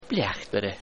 If you click on these Irish words, or any of the other words of the day, you can hear how to pronounce them.